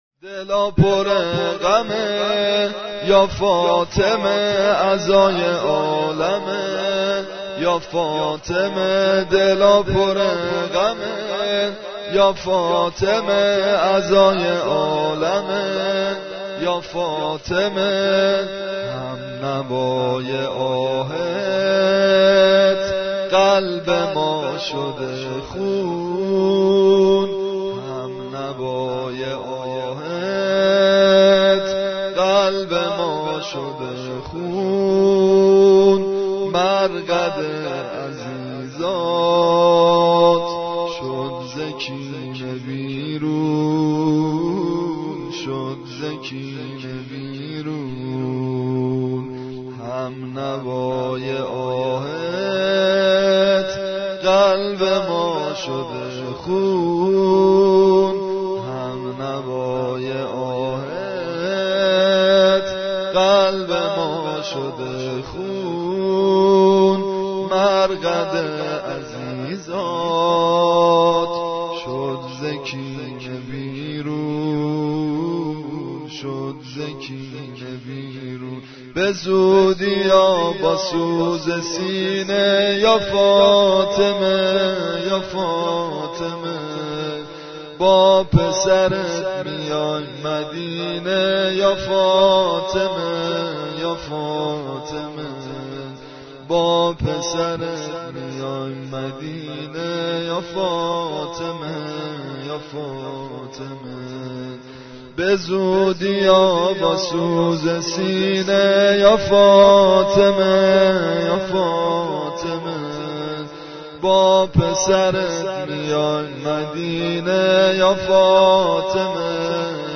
اشعار زمیته برای تخریب قبور ائمه با سبک -( دلا پر غمه یا فاطمه ، عزای عالمه یا فاطمه )